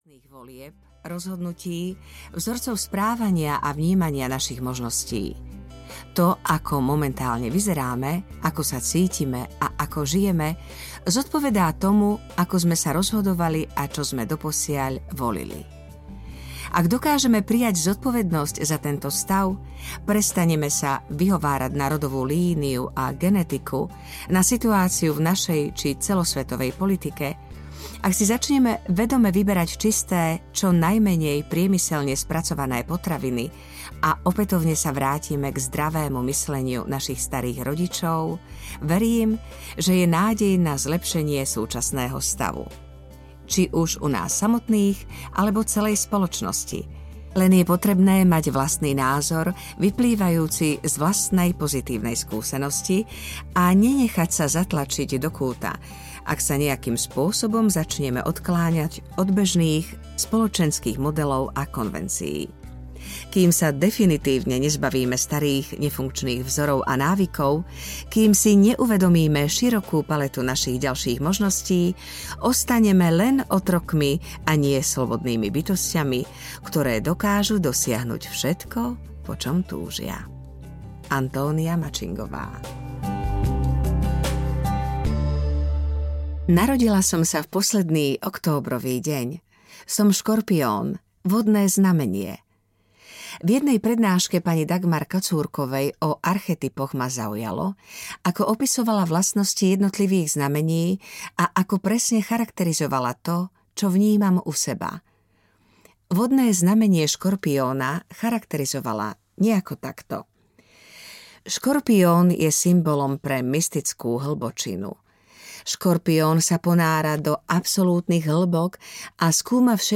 Najedzte sa do štíhlosti 3 audiokniha
Ukázka z knihy